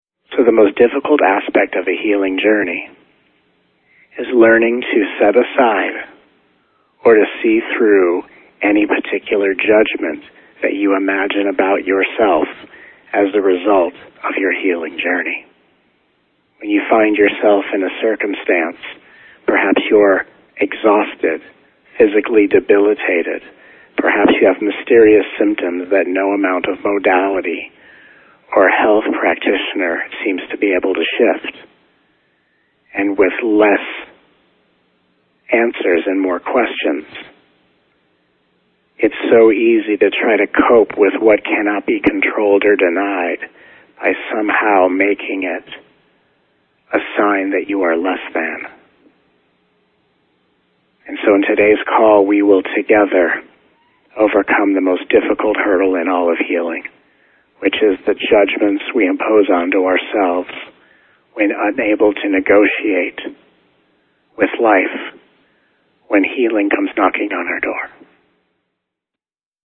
The Highest Vibration of All: 7-week Telegathering Series, Plus two bonus Calls.
Along with the transmission of healing energies, brand new teachings, and interactive experiences to awaken the radical alignment of your soul’s true purpose, each call has timely questions answered for the benefit of all.